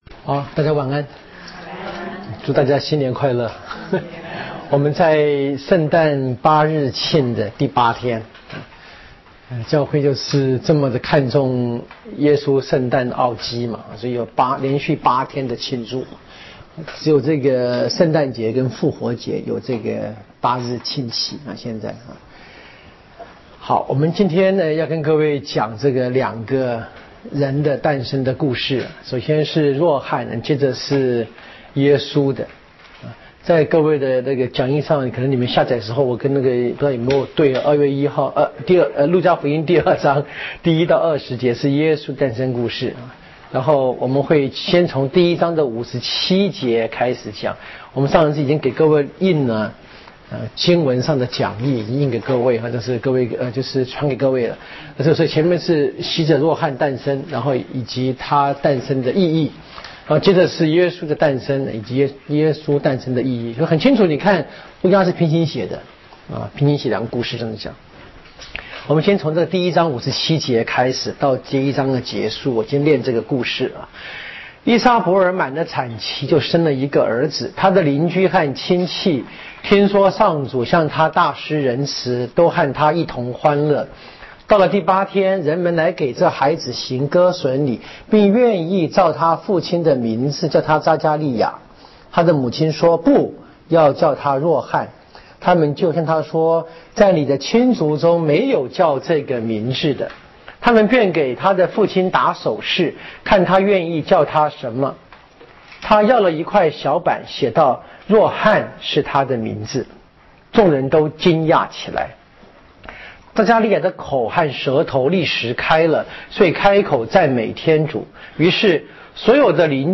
圣经讲座】《路加福音》